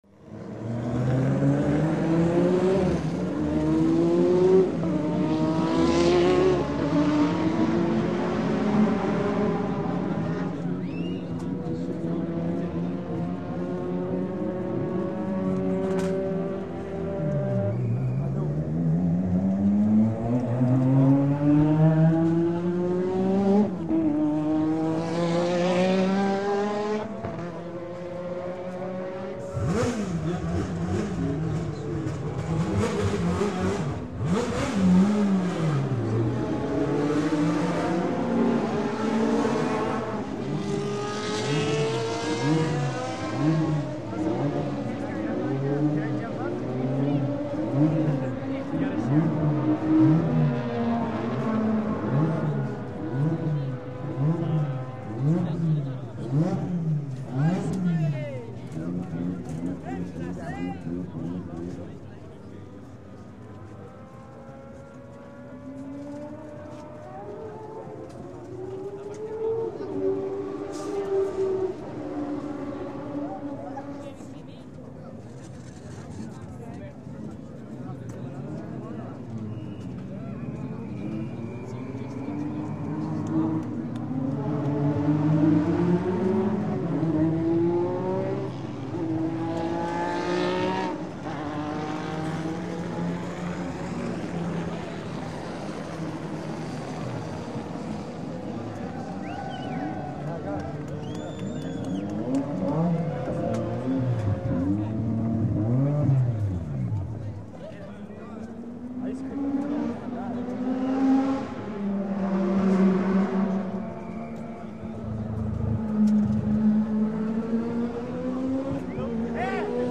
Звуки Формулы-1
Звук телеэфира Формулы-1 и голос комментатора